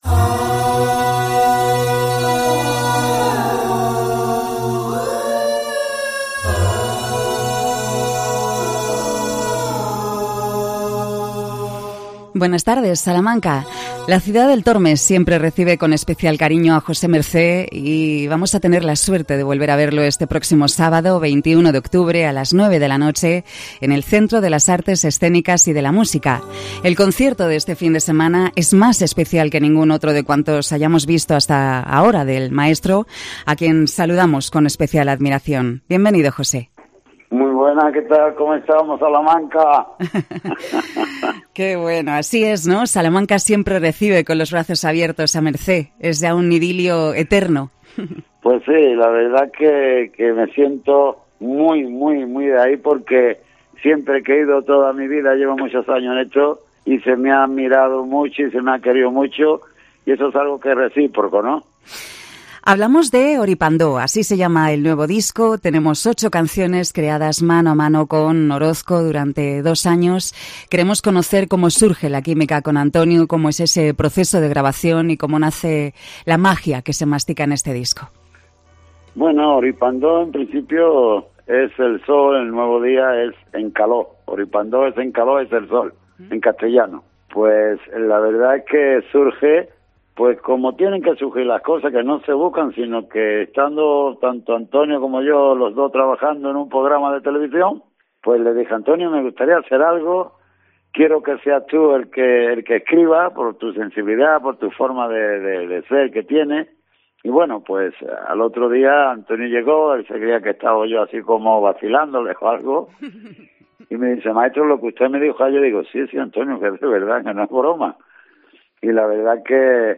COPE Salamanca entrevista a José Mercé